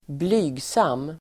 Uttal: [²bl'y:gsam:]